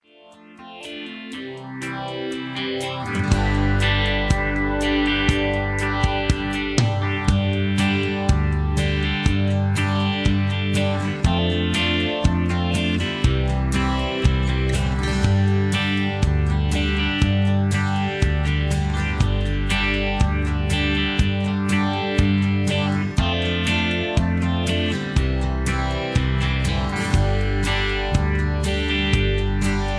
backing tracks, karaoke, sound tracks, studio tracks, rock